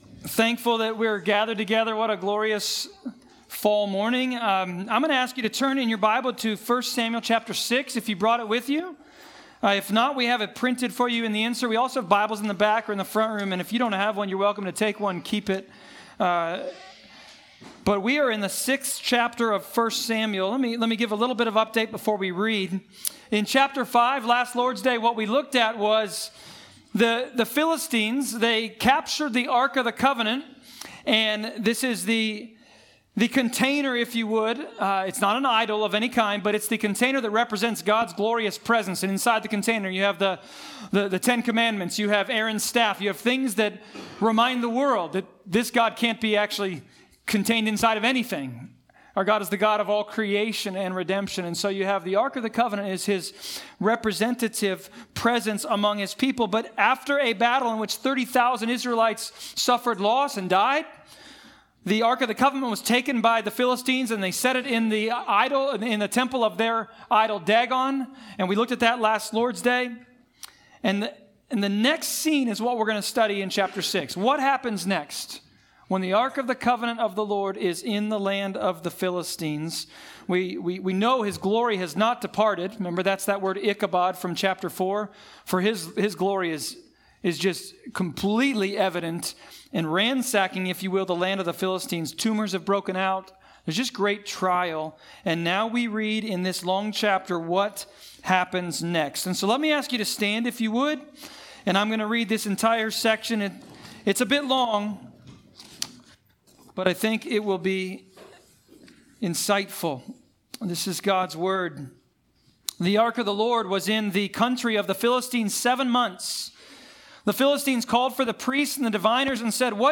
1 Samuel Passage: 1 Samuel 6.1-7.2 Service Type: Sermons « The Hand of the Lord.